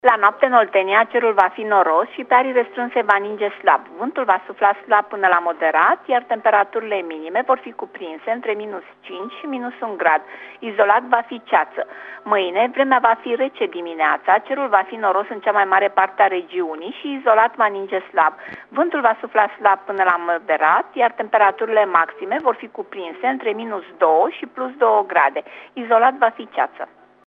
Meteo